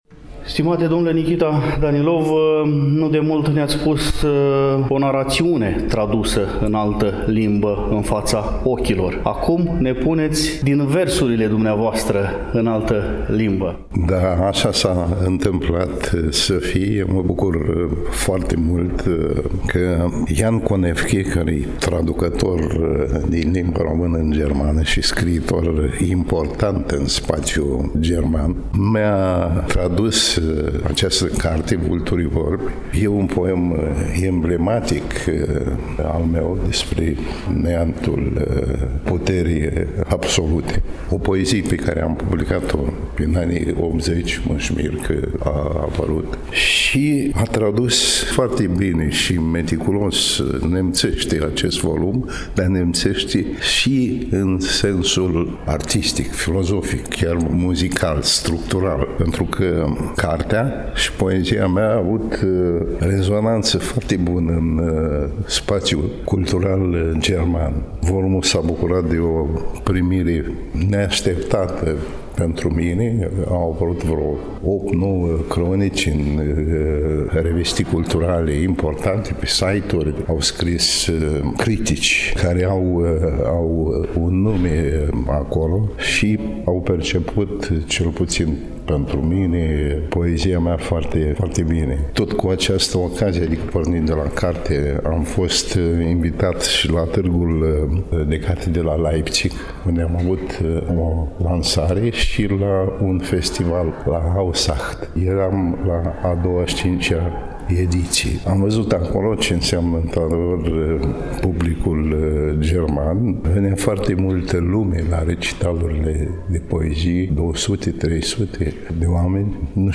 Înainte de prezentarea volumului, l-am invitat pe scriitorul Nichita Danilov la dialog. Aflăm amănunte despre poemul care dă titlul volumului de față; Nichita Danilov are cuvinte de apreciere pentru scriitorul Jan Koneffke, cel care a tradus volumul.
1_Poetul-Nichita-Danilov-INTERVIU-7-08.mp3